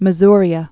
(mə-zrē-ə)